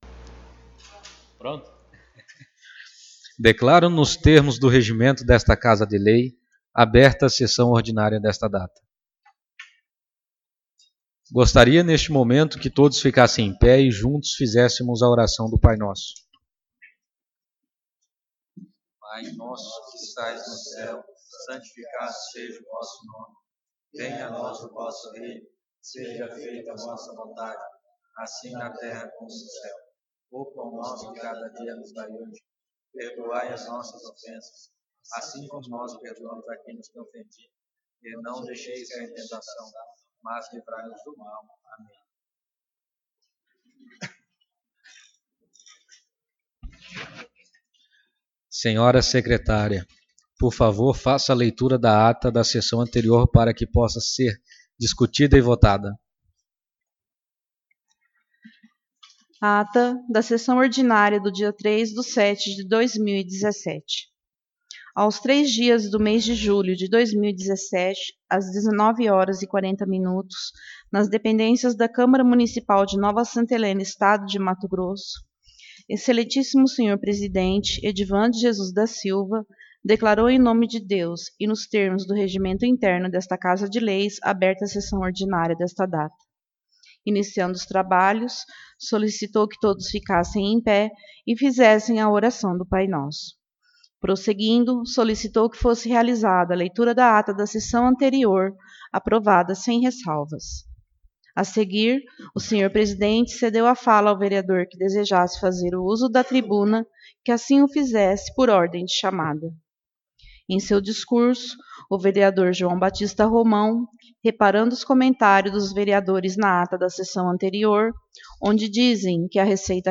Sessão Ordinária 10/07/2017